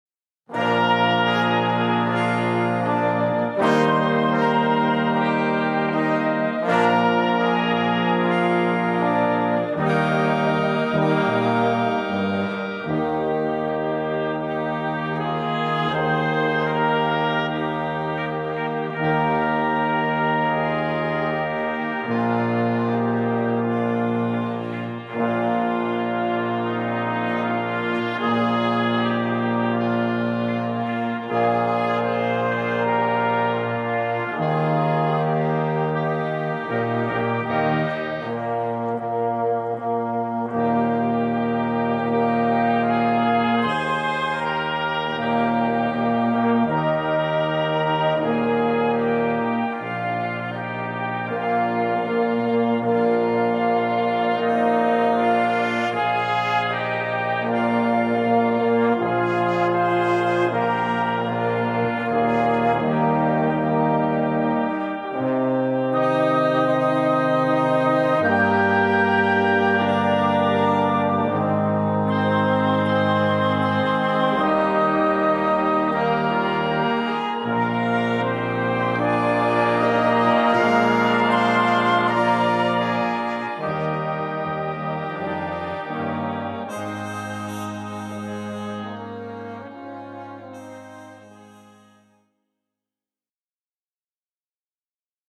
Besetzung: Blasorchester
Es ist leicht gehalten, hat aber eine große Wirkung.